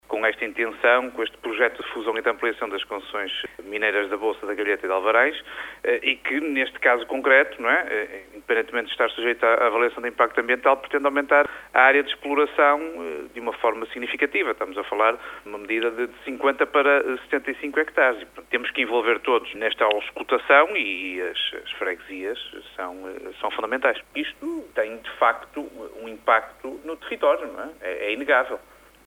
O PSD teme eventuais “impactes ambientais” para as comunidades locais por causa do projeto, e nesse sentido dirigiu ao presidente da comissão de ambiente, energia e ordenamento do território um requerimento para audição das várias partes envolvidas, nomeadamente os presidentes de Junta, como explica à Rádio Barcelos, o deputado barcelense na Assembleia da República, Carlos Eduardo Reis: